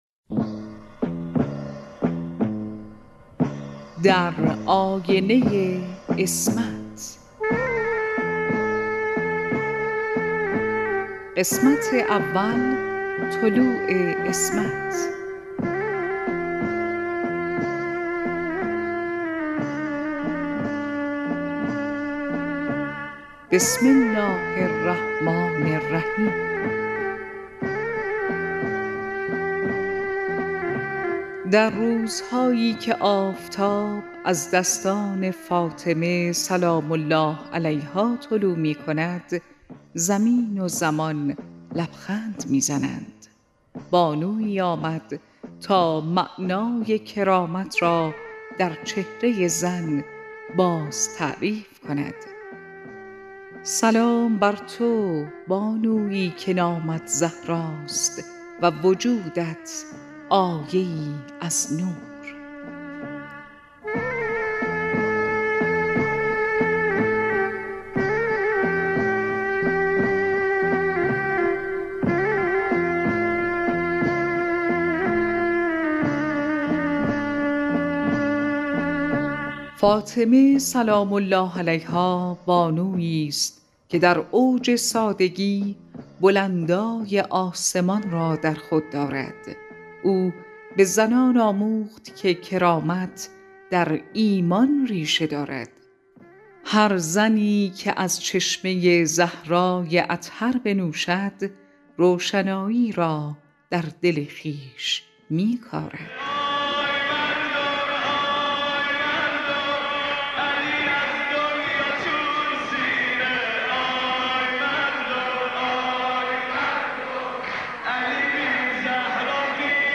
مجموعه رادیویی